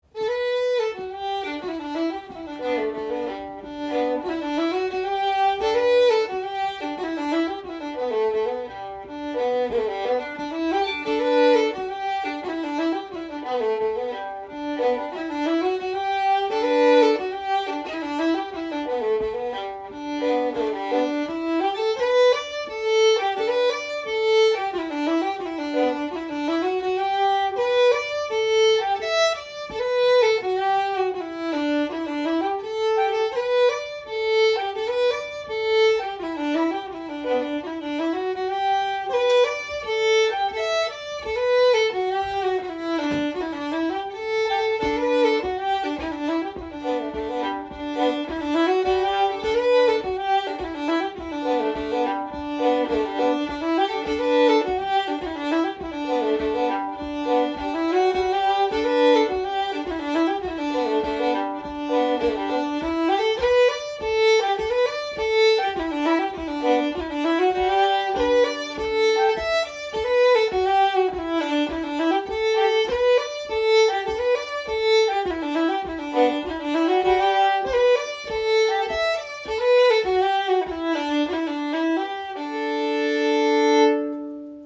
Sessions are open to all instruments and levels, but generally focus on the melody.
Type Reel Key G